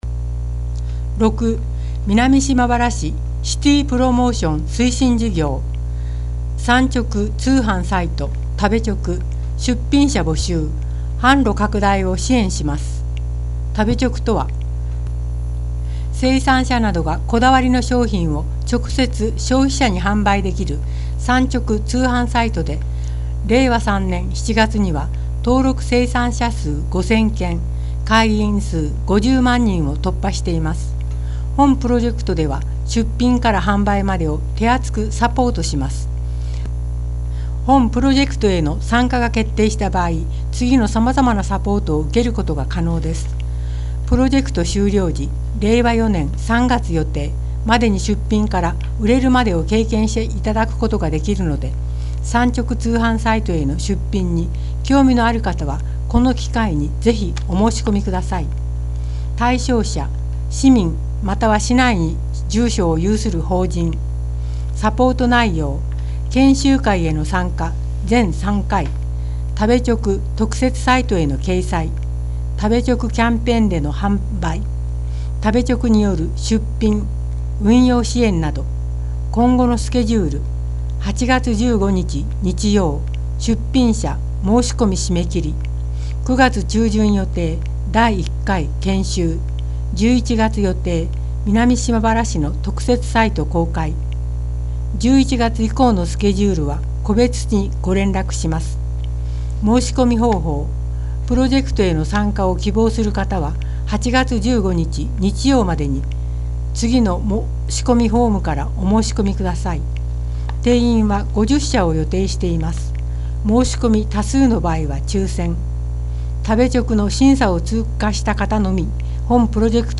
音訳（※声の広報紙）
この音訳データは、音訳ボランティア「うぐいすの会」の皆さんにより作成された音読データをmp3形式で提供しています。